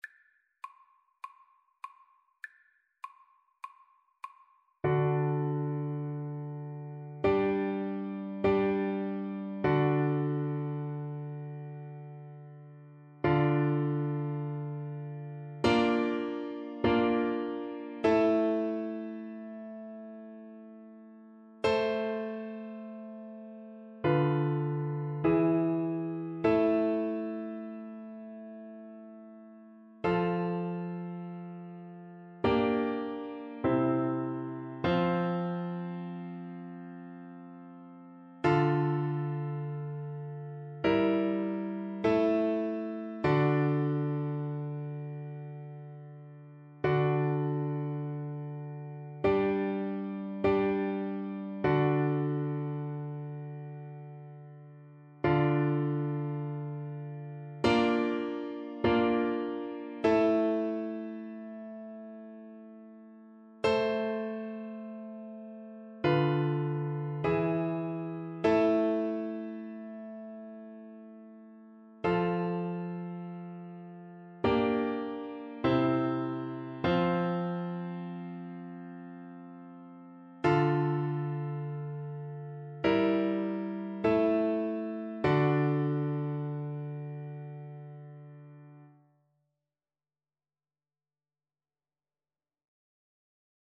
Soprano (Descant) Recorder version
4/4 (View more 4/4 Music)
G6-E7
Recorder  (View more Easy Recorder Music)
Classical (View more Classical Recorder Music)